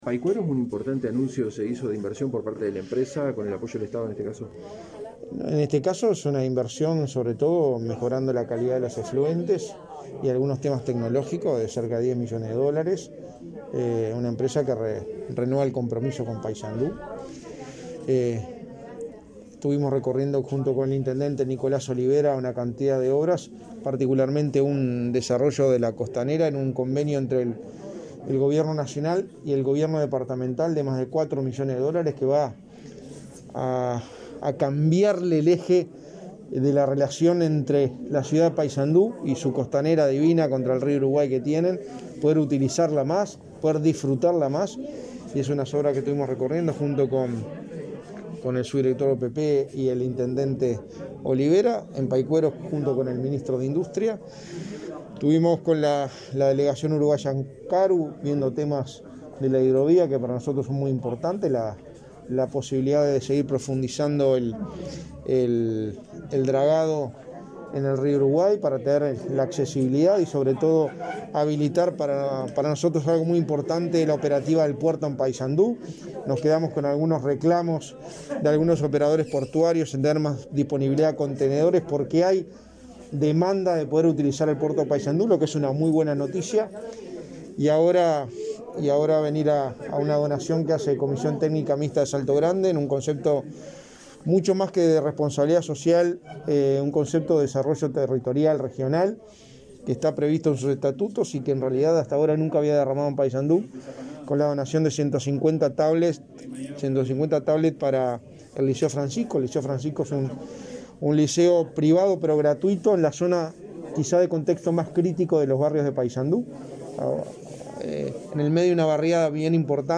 El secretario de la Presidencia, Álvaro Delgado, brindó una ronda de prensa al finalizar su visita por Paysandú, este miércoles 30.
Delgado_prensa.mp3